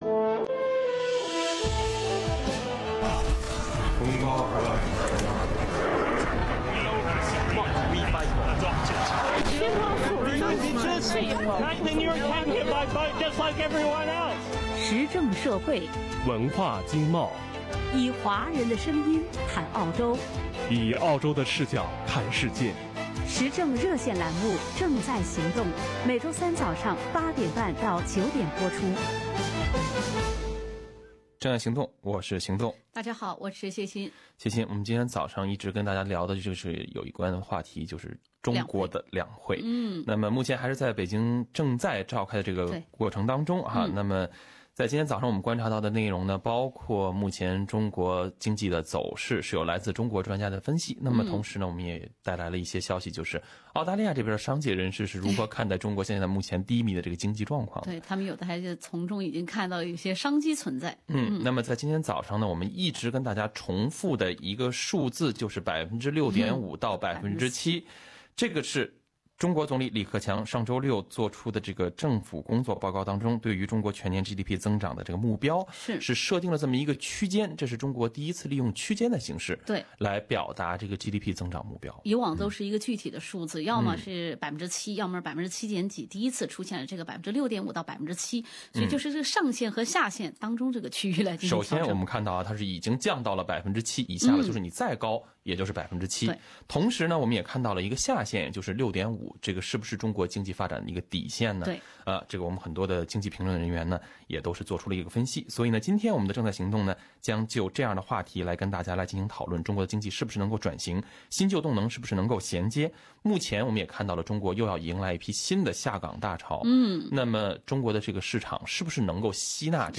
中国经济能否转型？新旧"动能"如何衔接？目前的市场能否吸纳新的下岗大潮？《正在行动》将就此展开讨论。